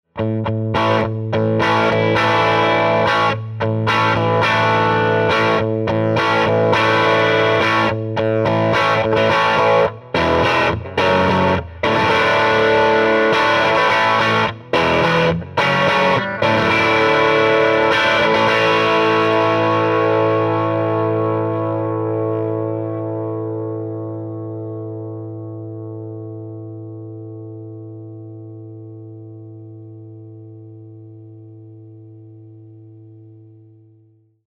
52T (1952 Tele lead) alone overdriven
50R_52T bridge overdriven.mp3